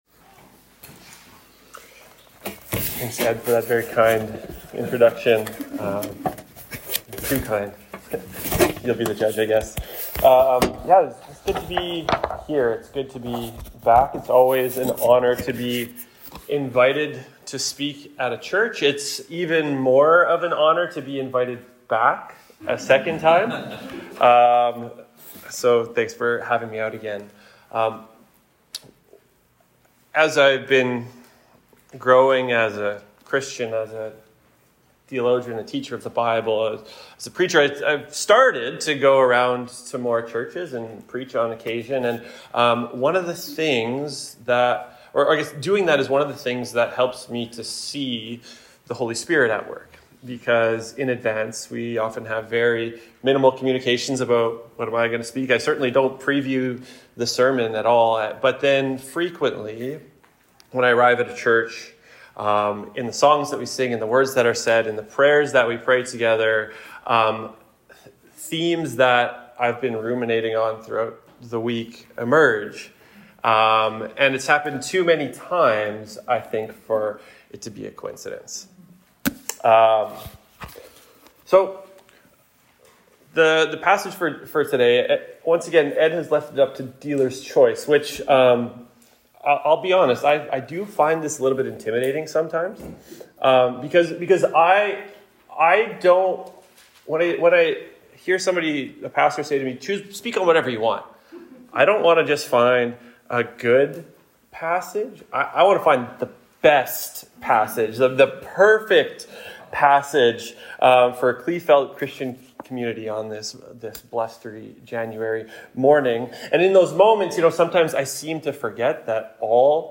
It’s my pleasure to be invited back to preach here.